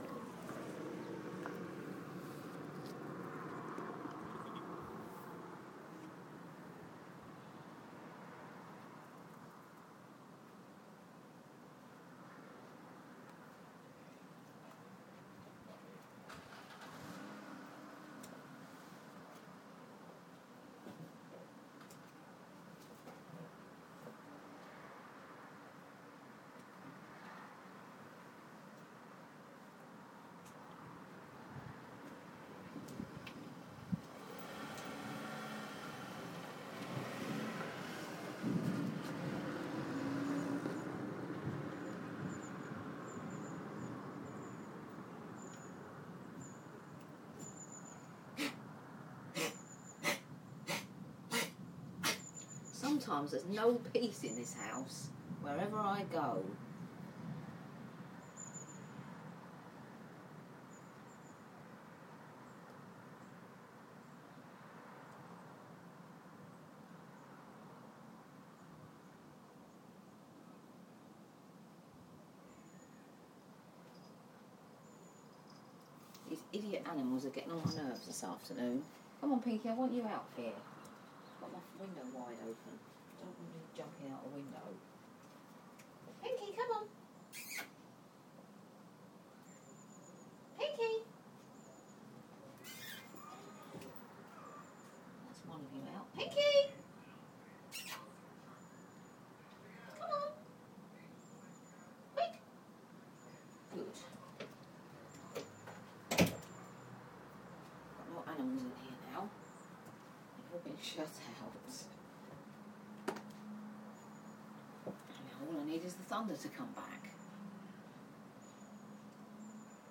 Recorded from my bedroom window, some sounds of Sunday evening, including soothing rain, a bit of thunder, and very little input from me!